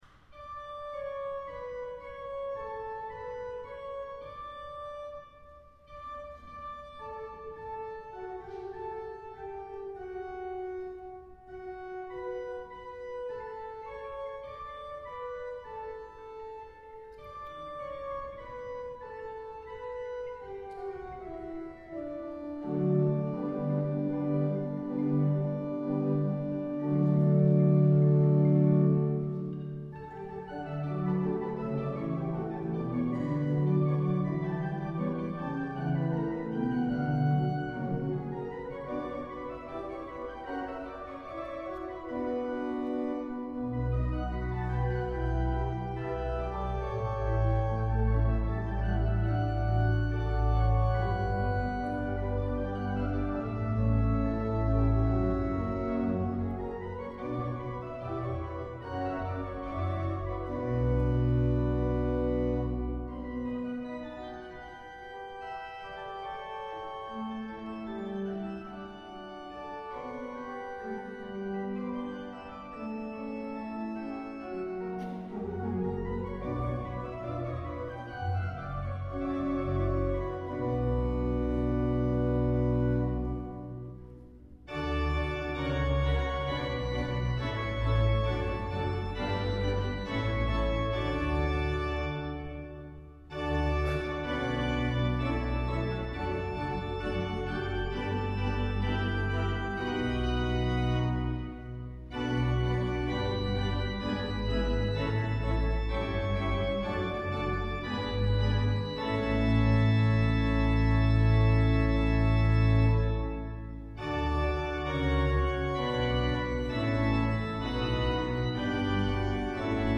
Concierto celebrado en Collbató del 50º aniversario del Órgano del Sol Mayor de Marbella.
Coral navideño alemán "Desde el cielo vengo" - Melodía de Martín Lutero
Arreglo para órgano